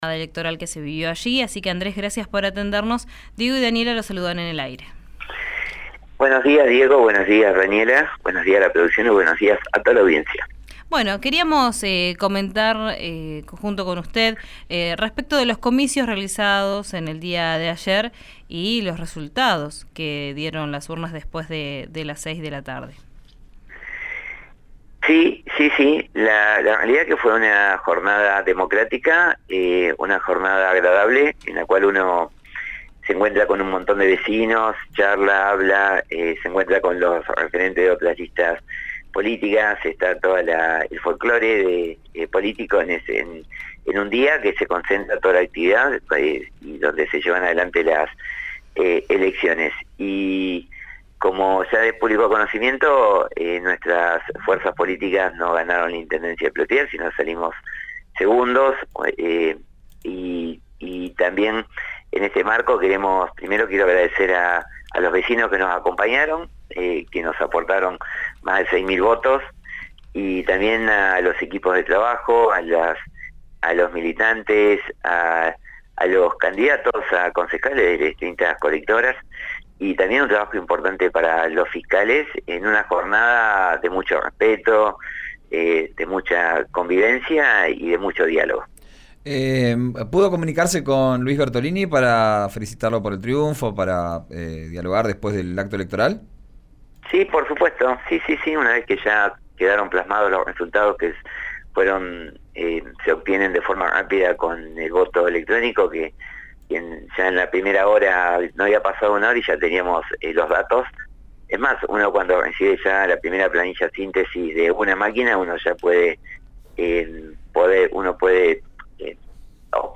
Escuchá a Andrés Peressini, exintendente de Plottier, en «Vos Al Aire» por RÍO NEGRO RADIO: